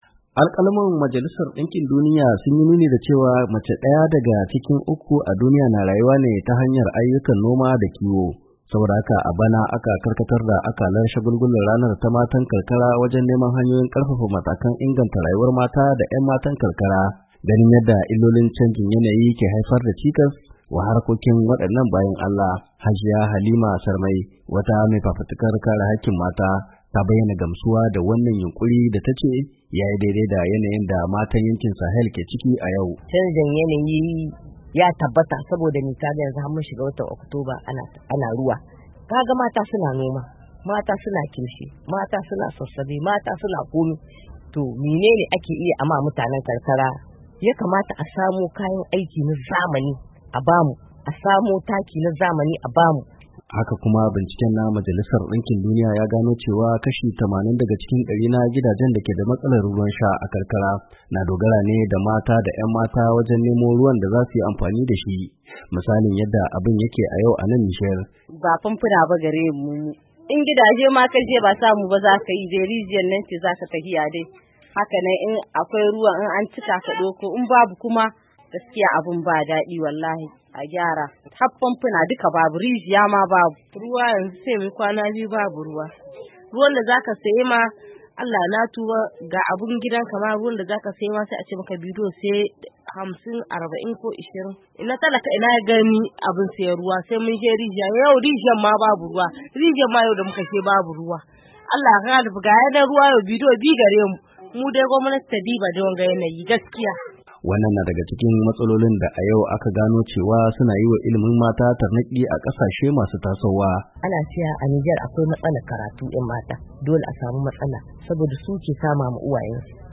Ga cikakken rahoto